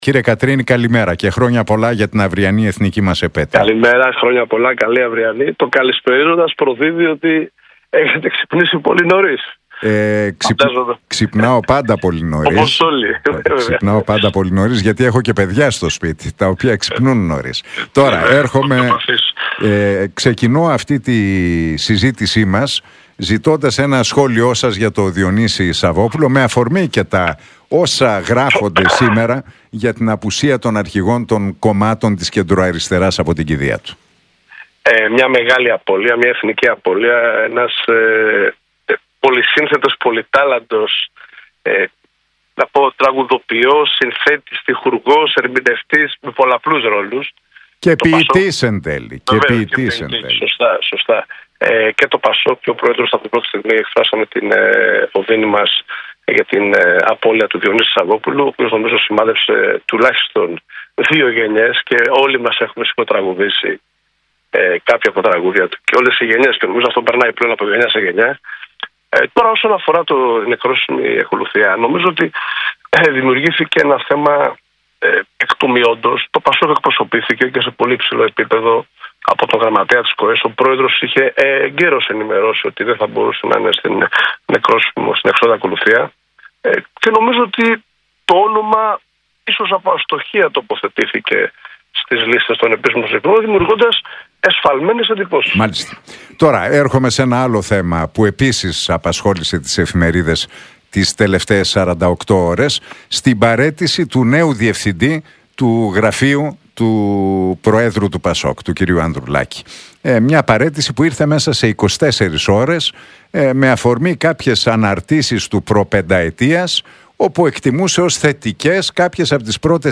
Για τον ΟΠΕΚΕΠΕ, τις αντιδράσεις για την τροπολογία για το Μνημείο του Αγνώστου Στρατιώτη και τις αυριανές παρελάσεις μίλησε, μεταξύ άλλων, ο βουλευτής του ΠΑΣΟΚ-ΚΙΝΑΛ, Μιχάλης Κατρίνης στον Νίκο Χατζηνικολάου από την συχνότητα του Realfm 97,8.